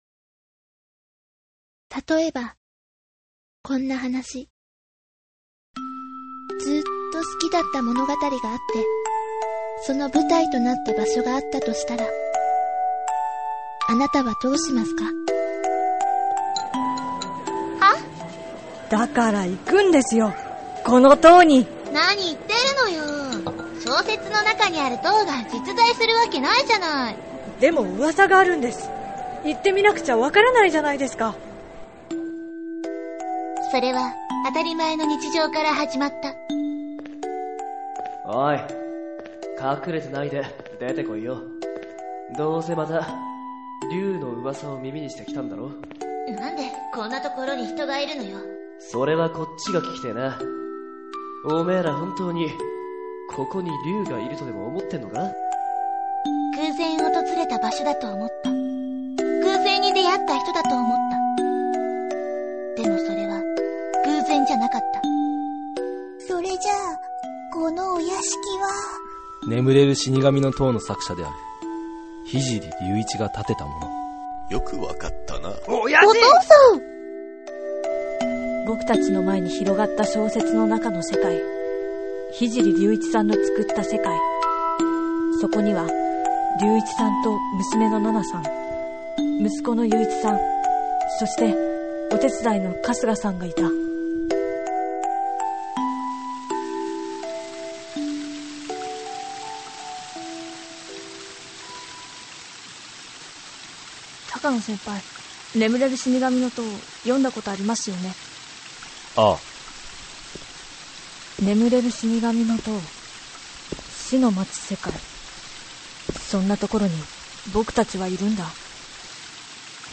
〜　作品ＣＭ　〜
〜　声の出演　〜